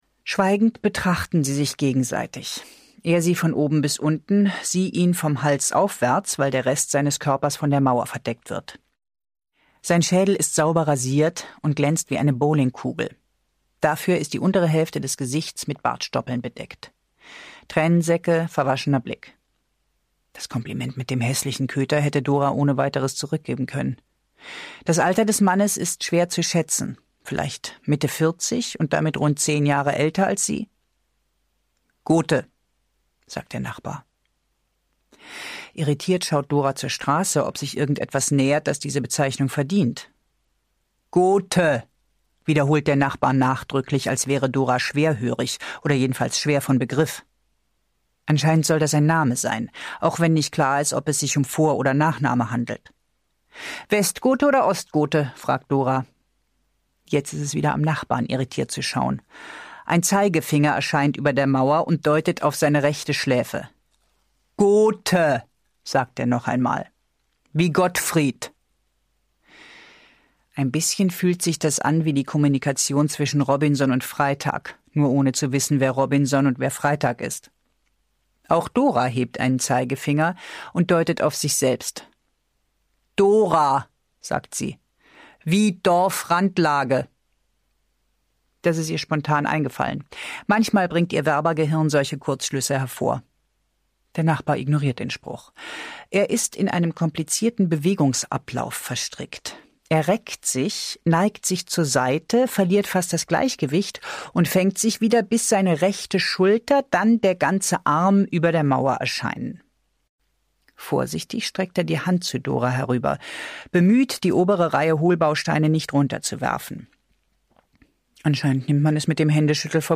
Über Menschen (mp3-Hörbuch)
Die ungekürzte Lesung wird von Anna Schudt vorgetragen.